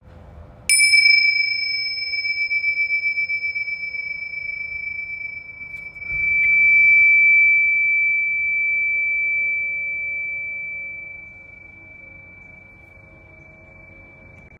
Tingsha Bells
Tingsha are two small metal cymbals stuck together to produce a clear and high-pitched tone. Different size tingsha produces different frequencies – large size tingsha emits a low-toned sound that vibrates for a long period whereas smaller size tingsha provides a high-pitched, pure sound.
75mm-tingsha.m4a